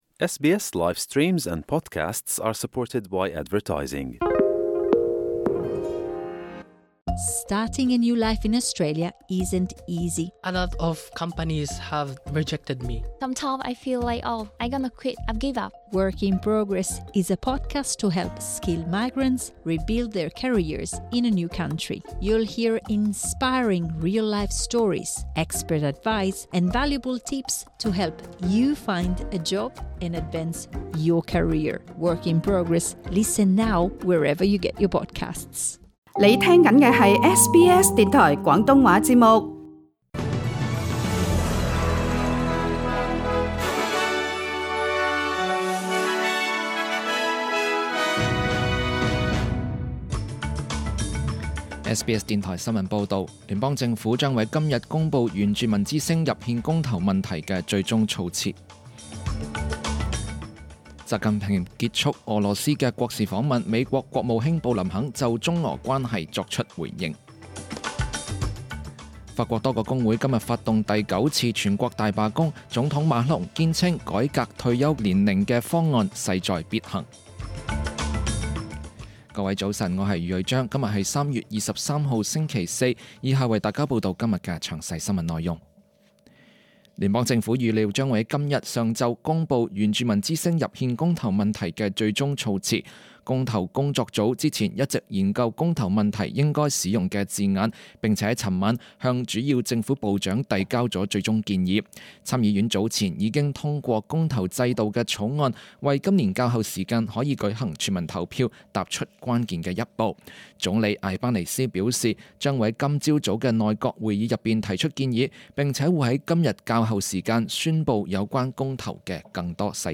SBS 中文新聞 (3 月 23 日)